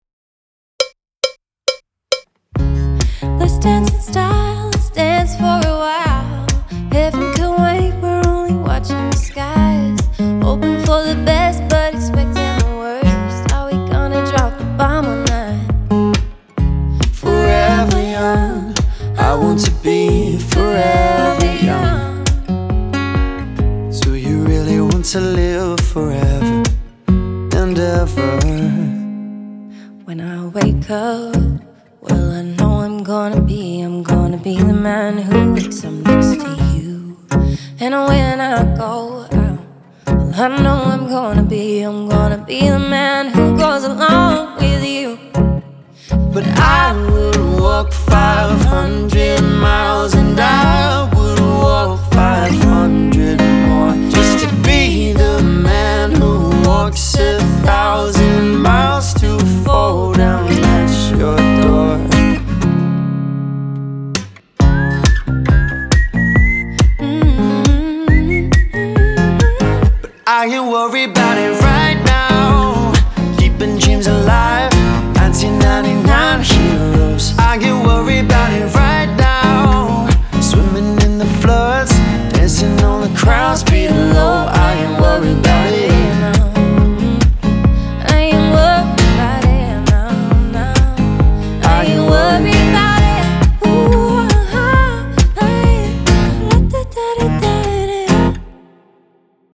Dual Vocals | Dual Guitars | Looping | DJ | MC